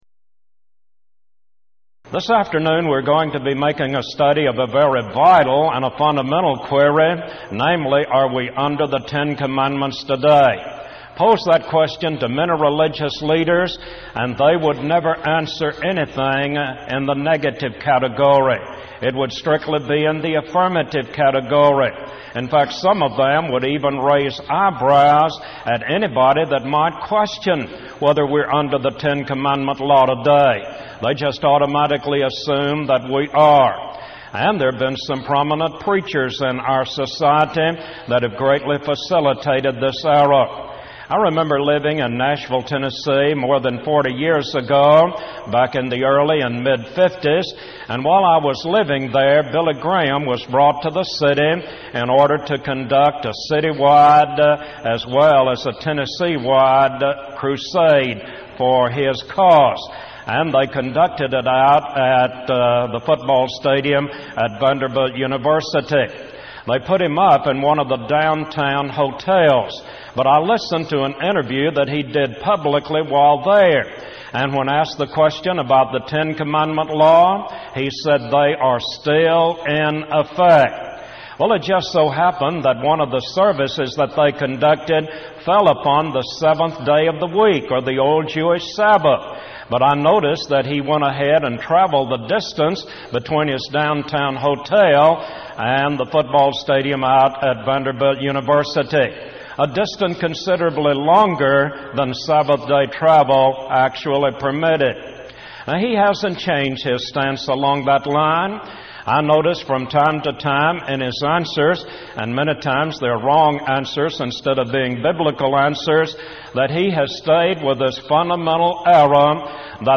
Event: 1996 Power Lectures
this lecture